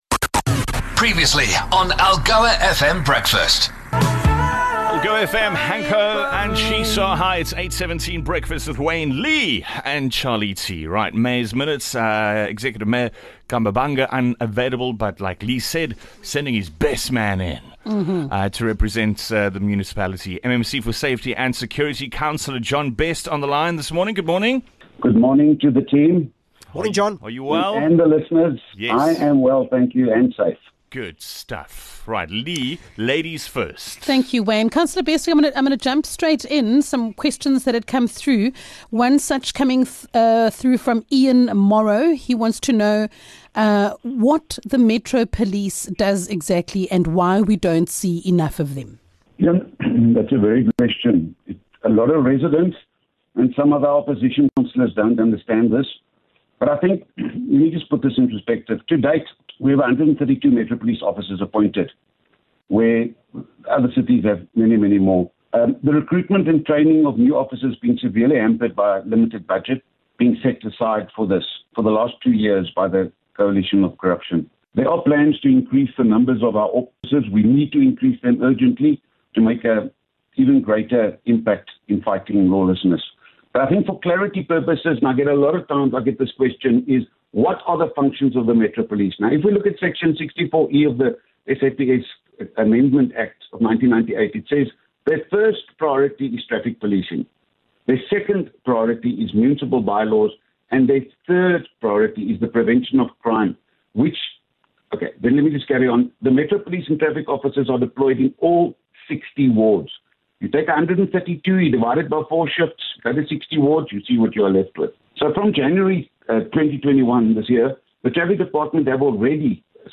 24 Jun MEC for Safety and Security, John Best, answers your service delivery questions